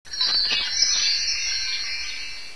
chimes.wav